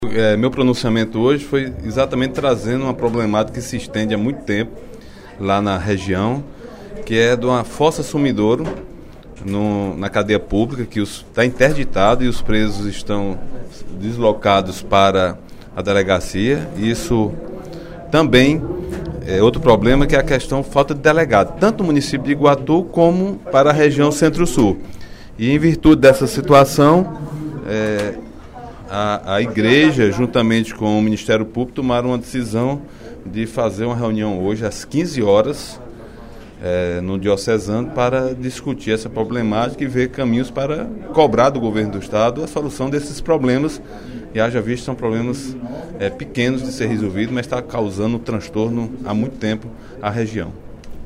O deputado Agenor Neto (PMDB) solicitou, durante o primeiro expediente da sessão plenária desta quinta-feira (18/02), um delegado de polícia para região centro-sul do Estado e outro para o município de Iguatu.